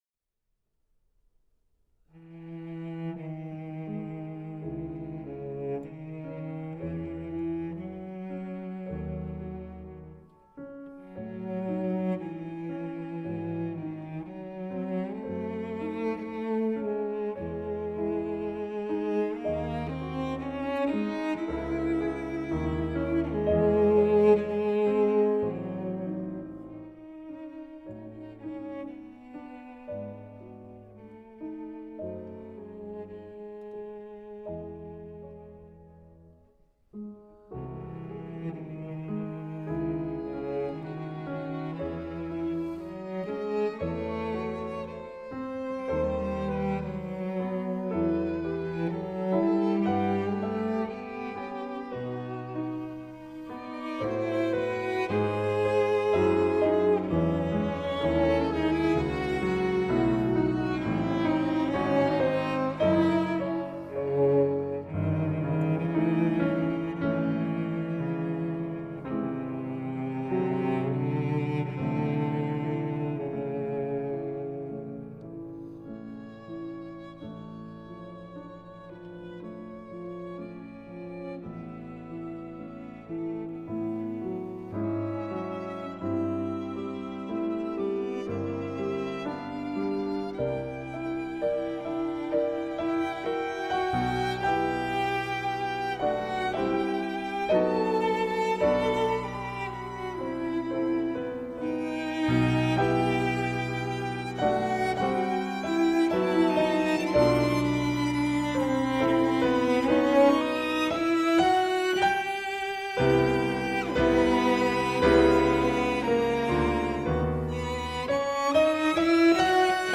violoncelle